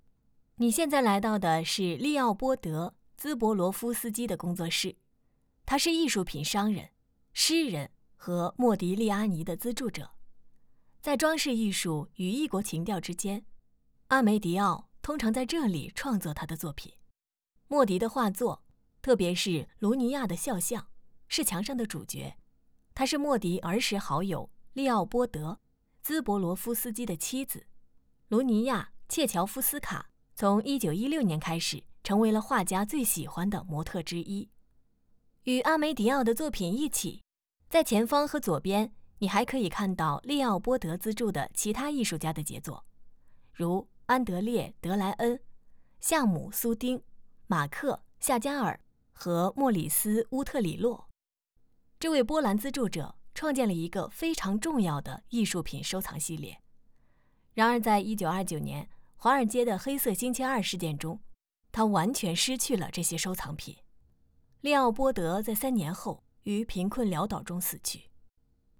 Chinese_Female_022VoiceArtist_3Hours_High_Quality_Voice_Dataset